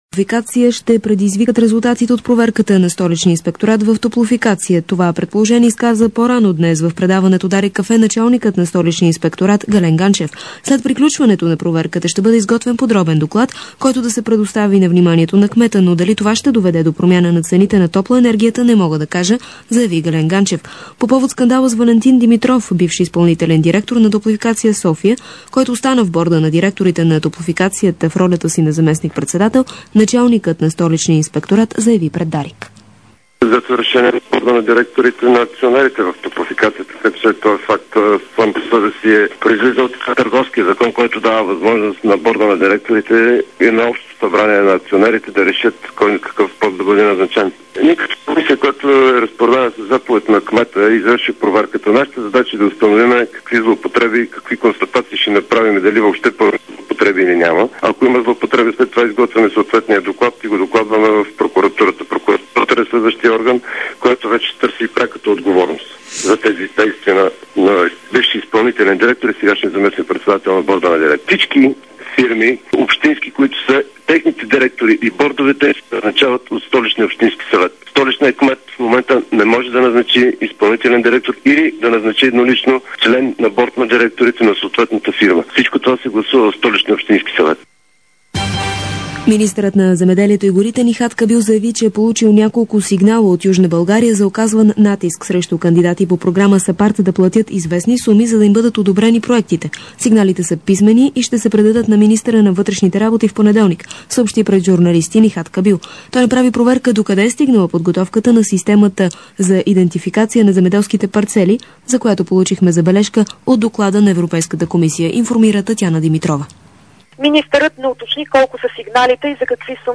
DarikNews audio: Обедна информационна емисия 18.05.2006